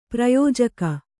♪ prayōjaka